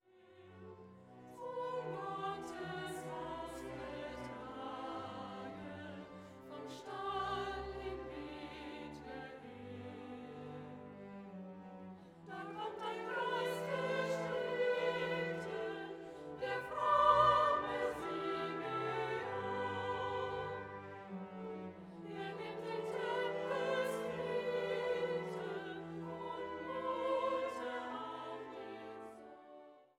für Sopran, Bariton, Frauenchor und Orchester